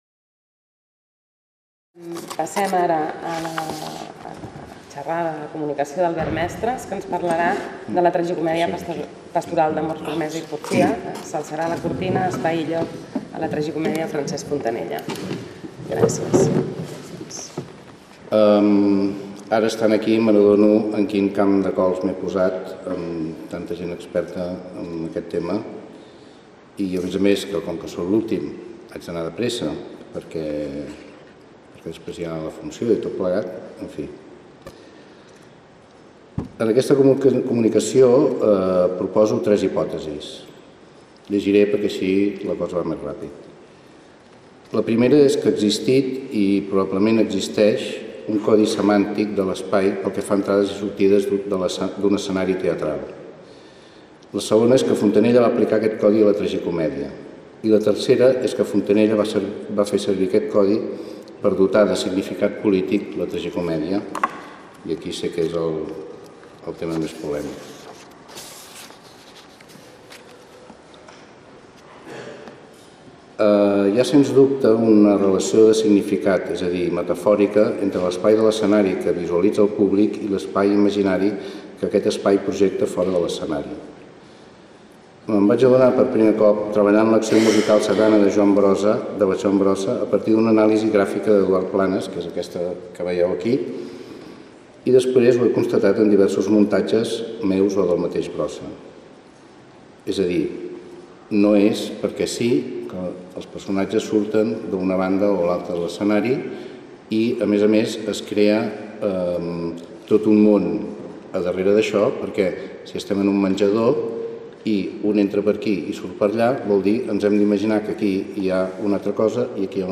Comunicació: “S’alçarà la cortina”: espai i lloc a la Tragicomèdia pastoral d’amor, firmesa i porfia de Francesc Fontanella